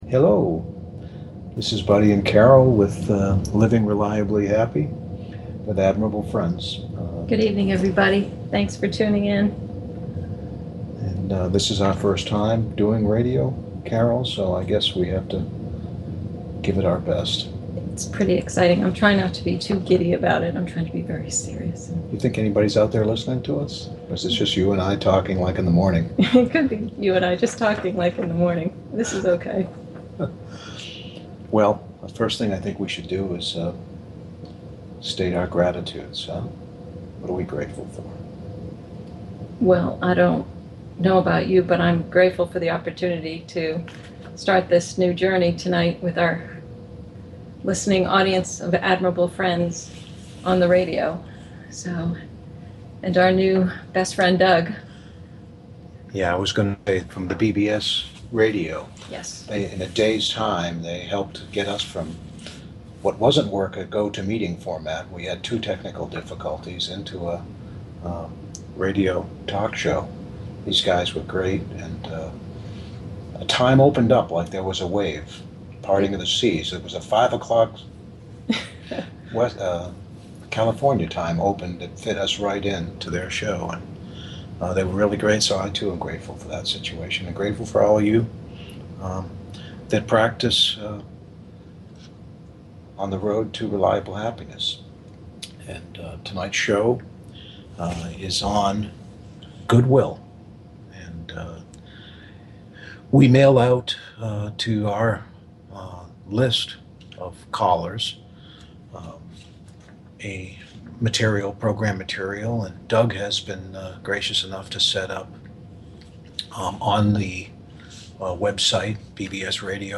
Tonight's Admirable Friends Talk Show is on HOSTILITY AND GOODWILL